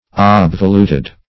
Obvolute \Ob"vo*lute\, Obvoluted \Ob`vo*lu"ted\, a. [L.